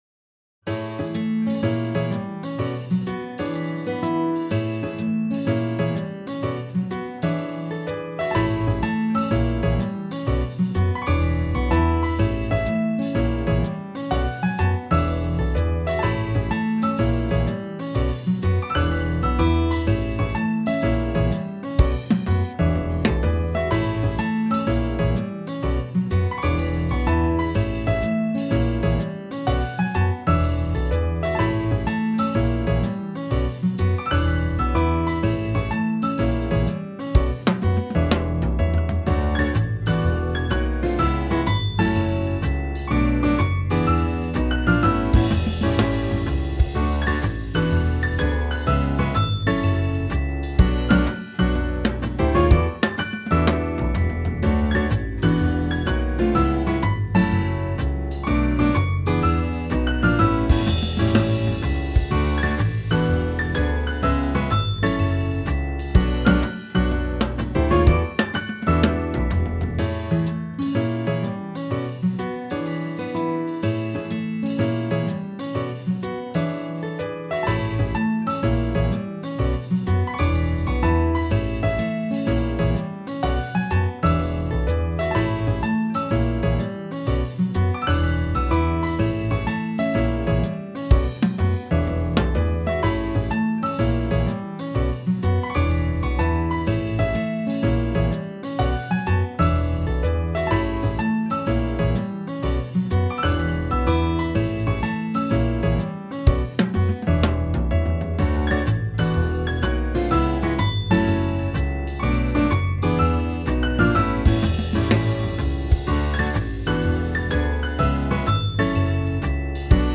お気に入りのカフェでお気に入りの席、お気に入りのホットコーヒー、そんな最高の時間をまったりくつろいでいる。
フリーBGM